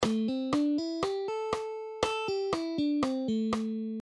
【ラドレミソ】と弾けばマイナーペンタトニックスケールとなります。
Minor Penta Tonic Scale
マイナーペンタ.mp3